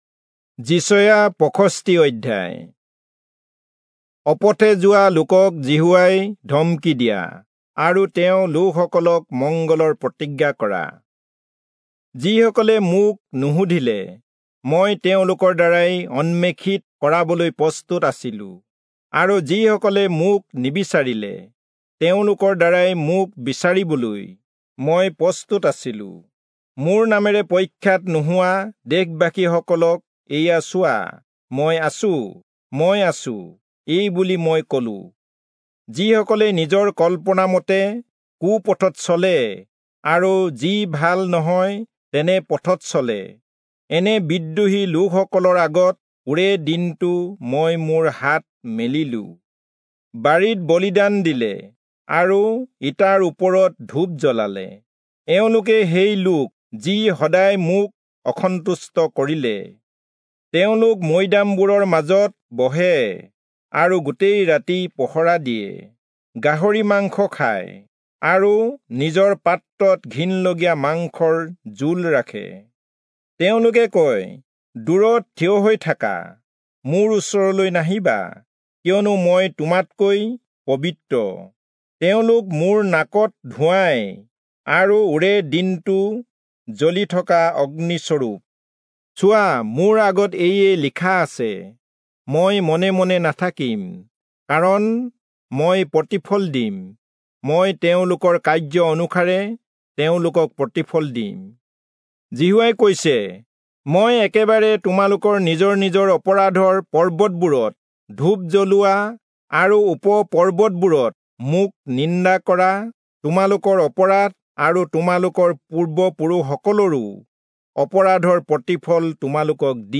Assamese Audio Bible - Isaiah 50 in Irvas bible version